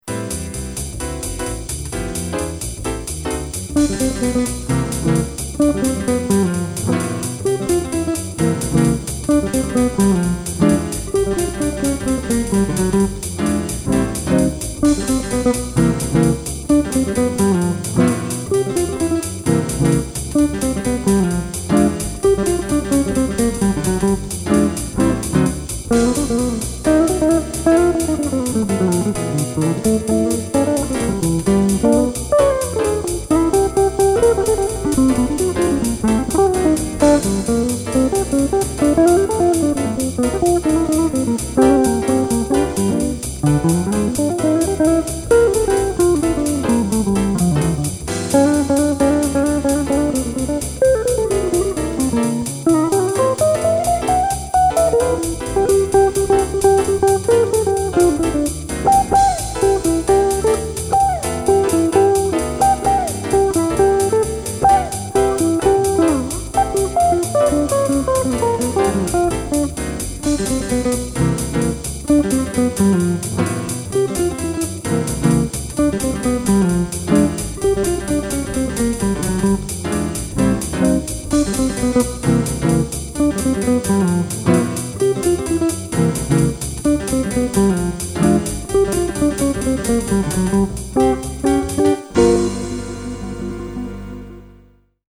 "Mr. Devious" is one of my most unique jazz swing blues originals. Despite the quick tempo (250+ bpm), the head uses just one left-hand finger combined with an open G string throughout to make the job easy.
Guitar doubles with keyboard on the melody.